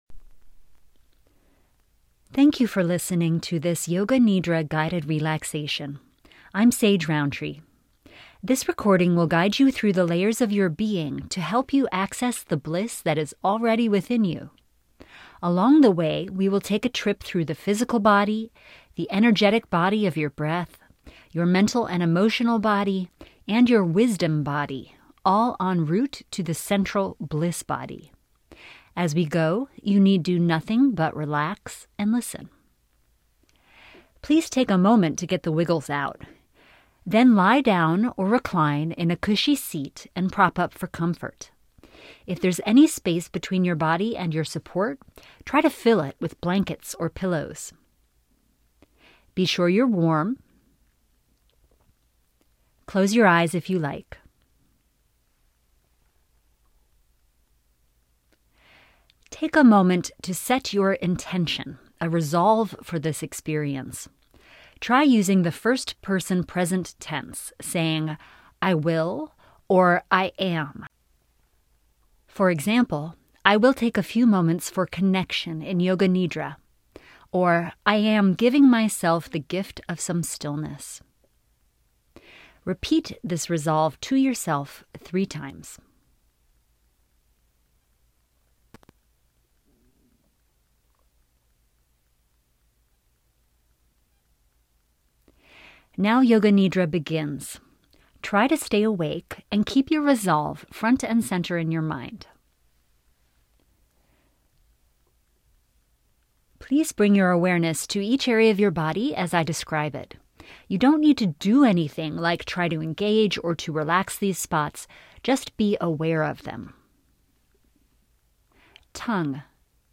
yoga nidra guided relaxation (audio file)